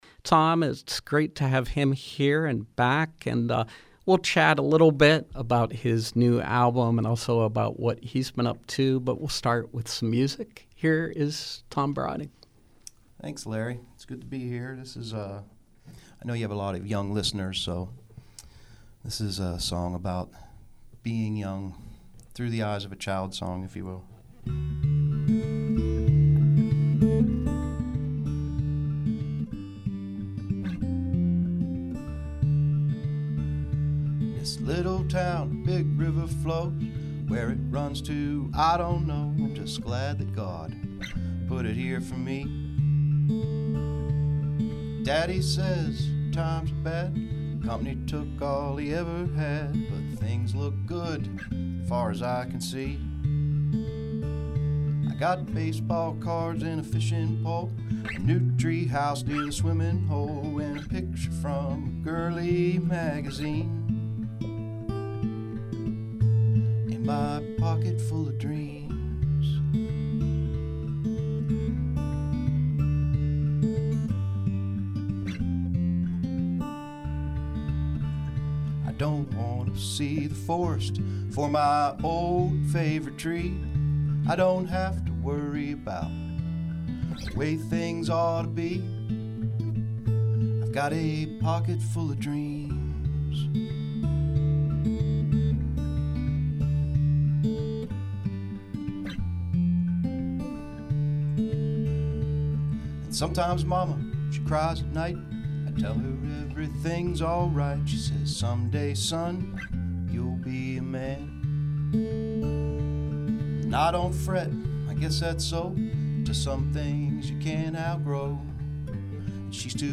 folk singer/songwriter